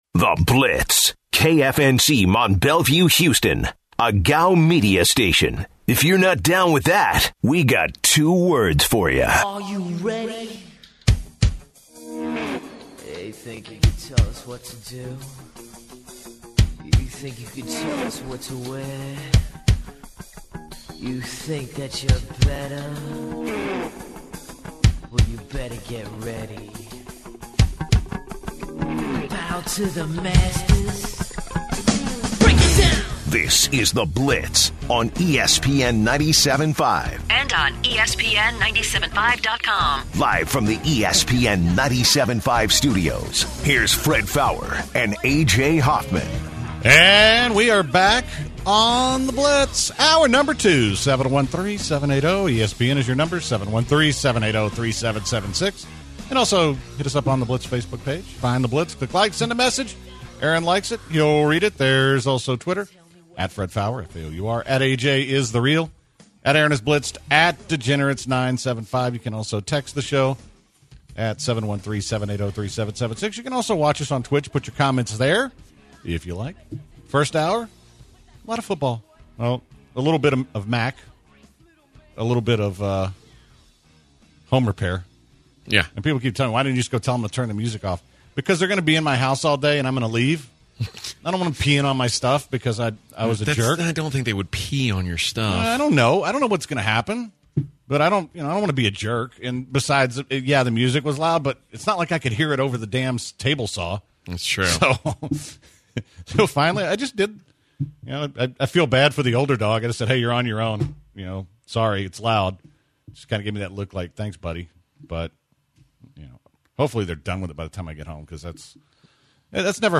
Headliner Embed Embed code See more options Share Facebook X Subscribe The guys start the second hour of the show talking television and look at the Texans’ Sunday night matchup against the Patriots.